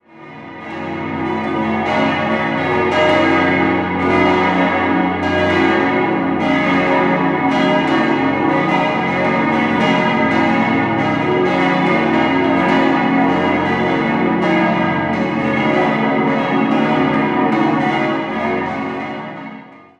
Das Geläut gehört zu den mächtigsten Glockenensembles der Schweiz. 6-stimmiges Ges-Dur-Geläute: ges°-b°-des'-es'-ges'-as' Die Glocken wurden 1950 von der Gießerei Rüetschi in Aarau gegossen.